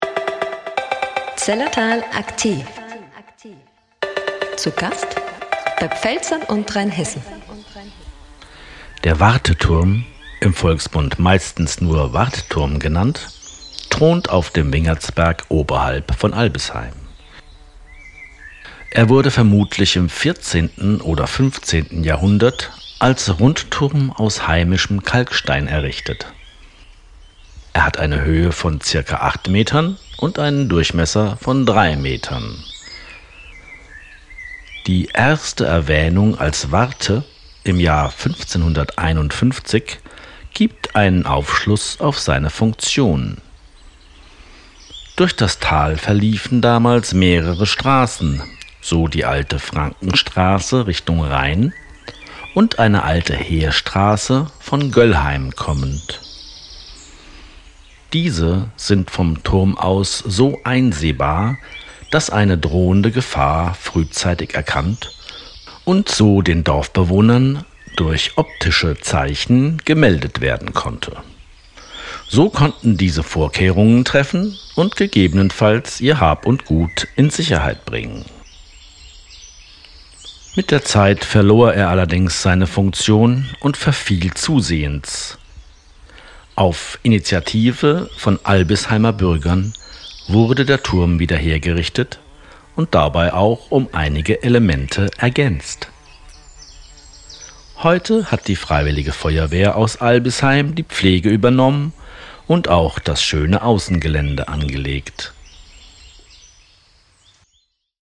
AUDIO-GUIDES